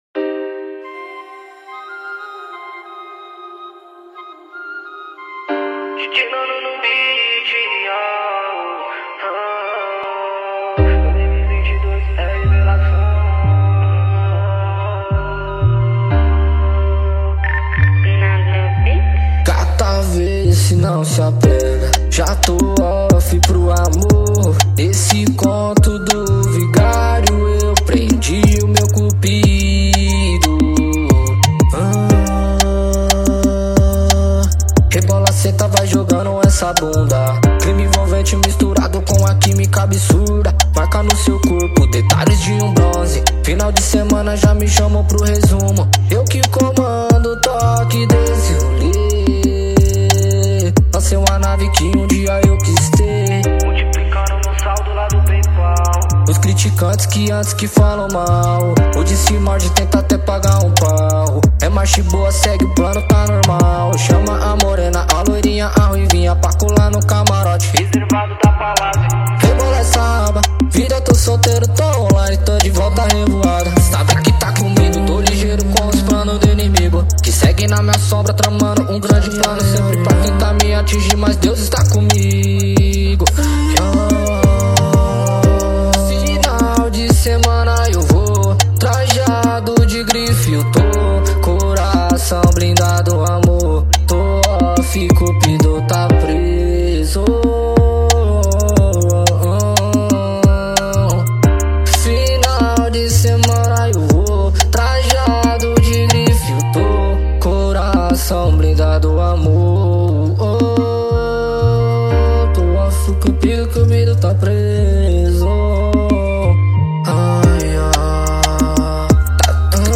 EstiloTrap